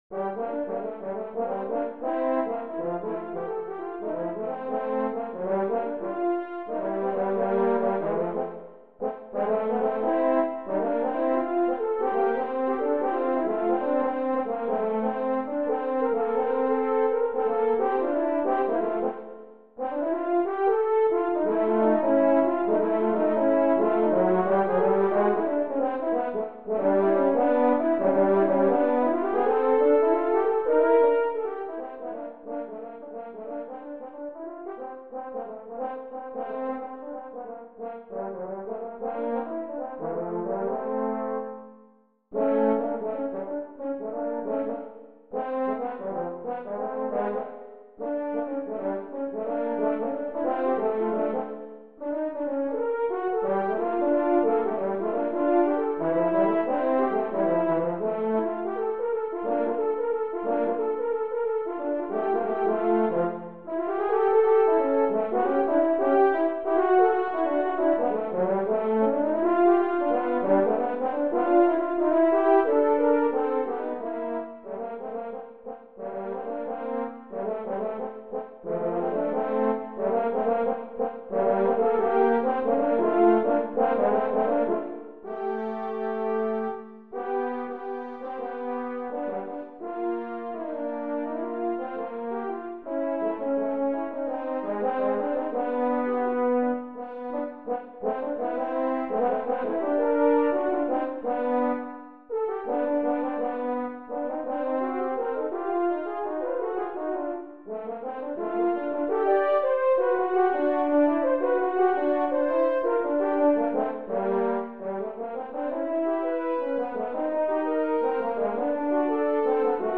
Voicing: French Horn Duet